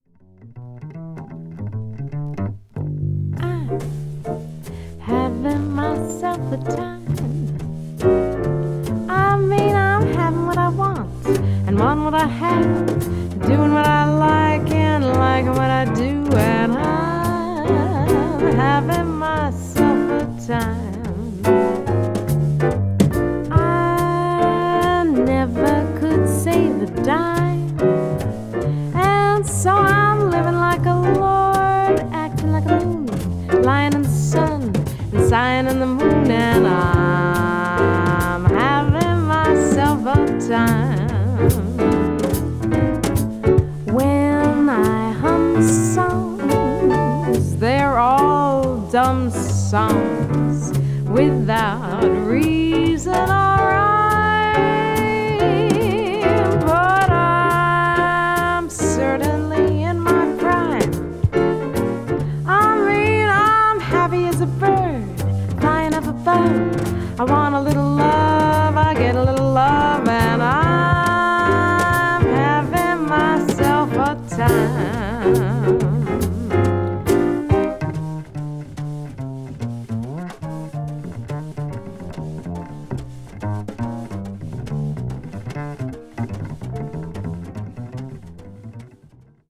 jazz standard   jazz vocal   modern jazz   piano trio